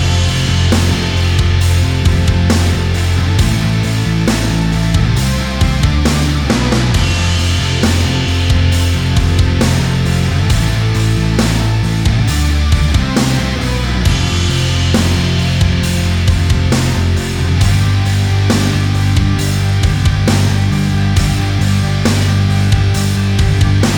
Minus Lead Guitars For Guitarists 4:38 Buy £1.50